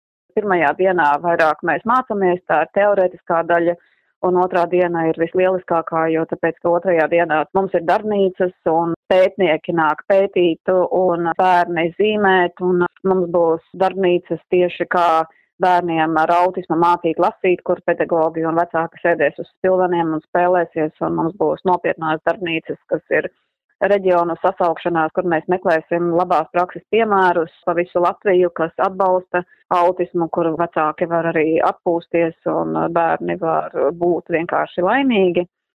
Saruna